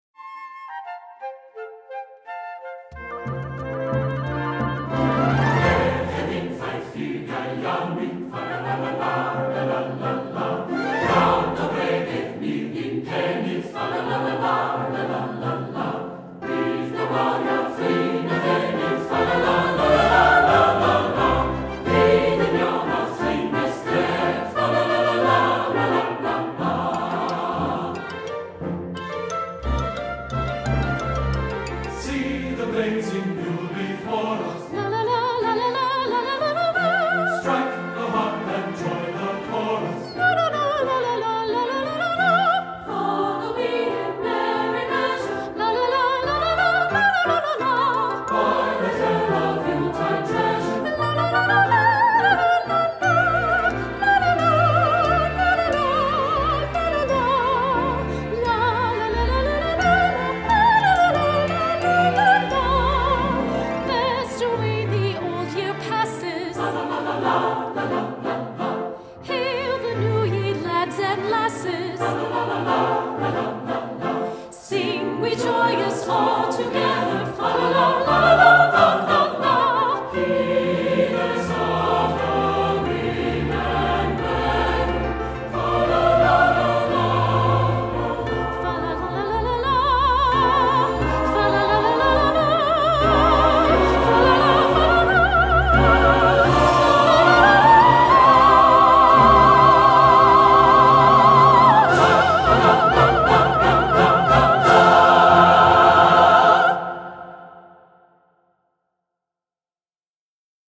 Voicing: SATB,Soprano and Piano